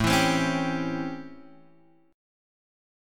A7#9 chord {5 7 5 6 5 8} chord